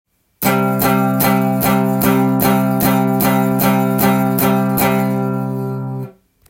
良いパワーコードも弾いてみました！
開放弦の響きが全くなくなりました。